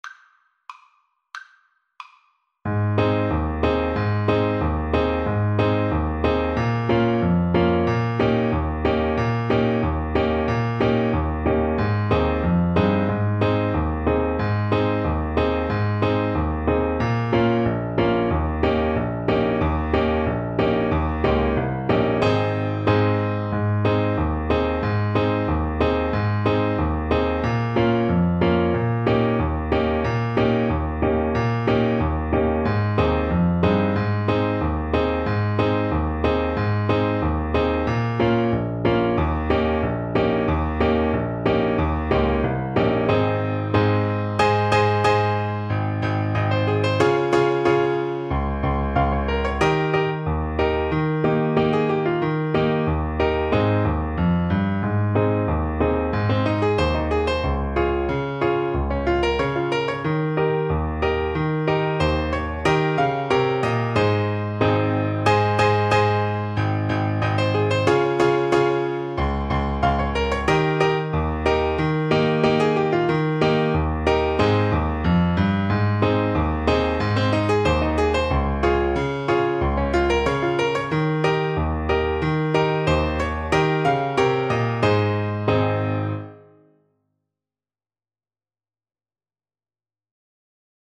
Traditional Music of unknown author.
2/4 (View more 2/4 Music)
Moderato =c.92